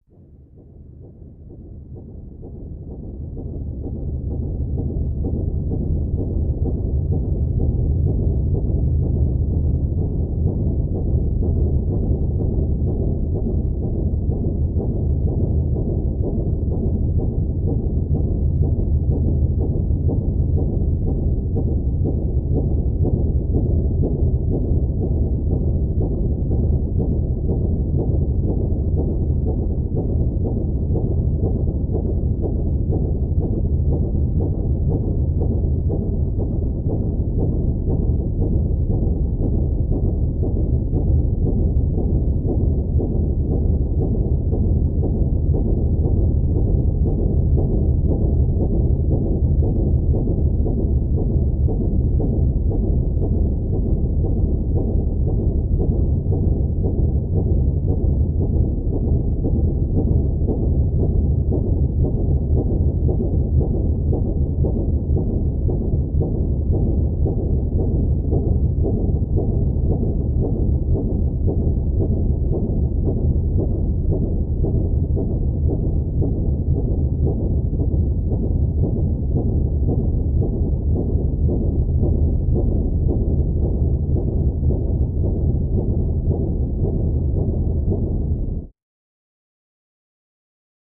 Ambiance, Machine Pulse; Regular Rhythmic Pulse Heavy Machine Medium Cu